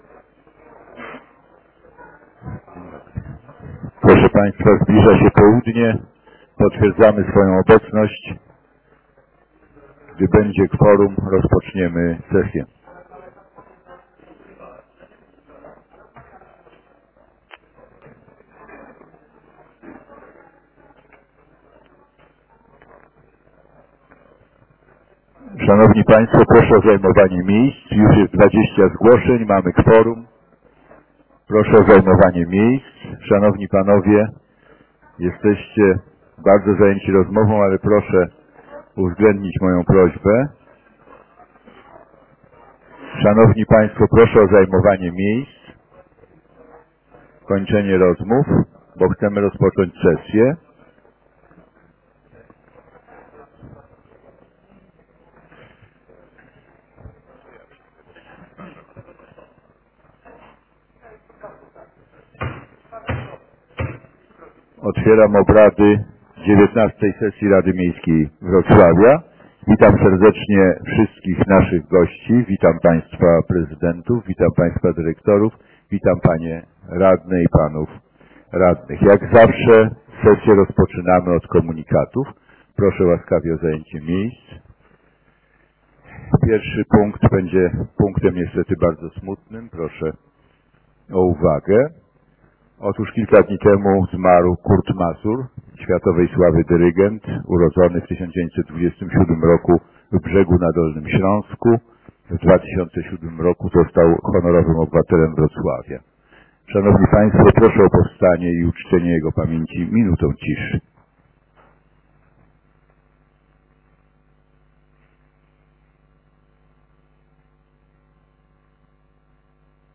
Porządek obradSesja XIX Rady Miejskiej Wrocławia22 grudnia 2015 roku, godz. 12:00w sali 200, Sukiennice 9 we Wrocławiu